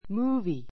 múːvi ム ーヴィ